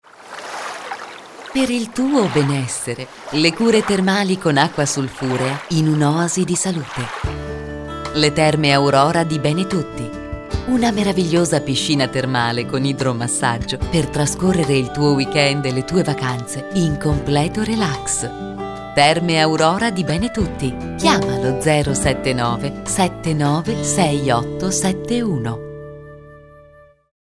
Spot Istituzionale